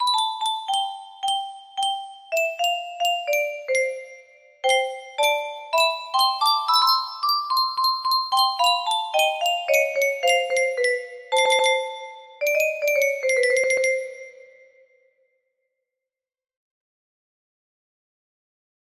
jolli time music box melody
Full range 60